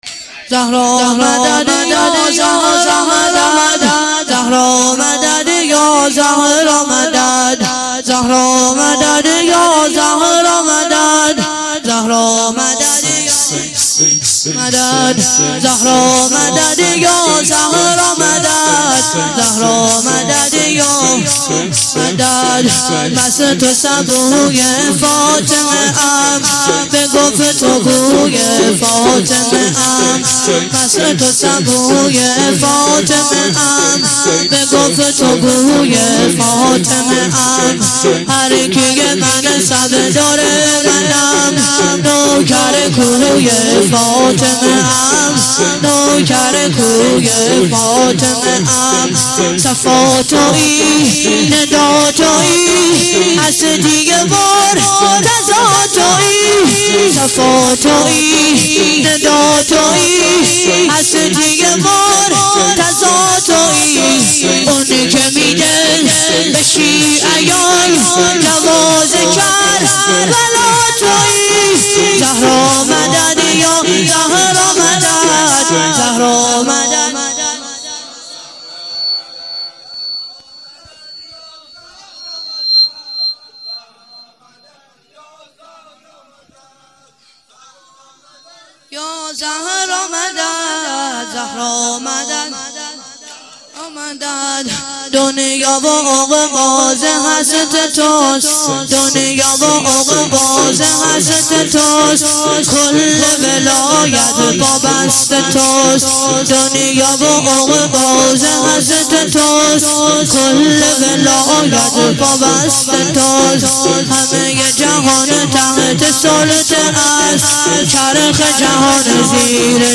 شور - زهرا مدد یا زهرا مدد